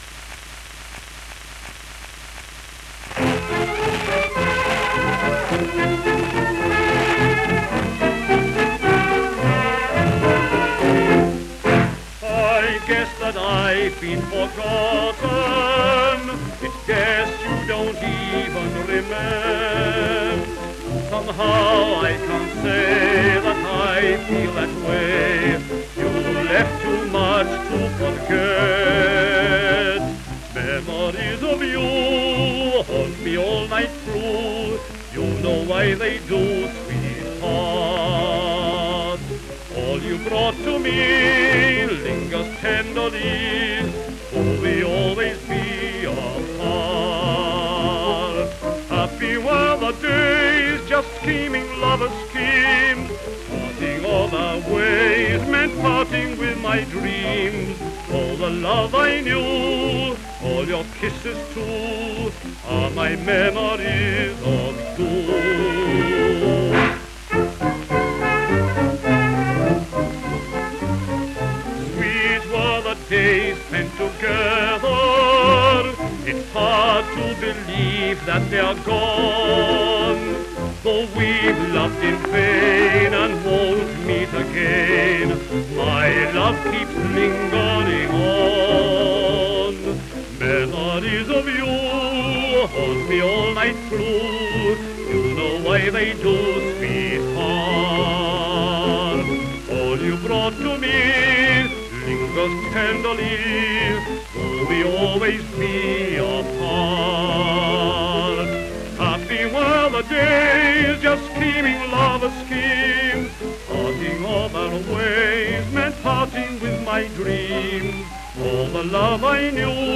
Orchestral Accompaniment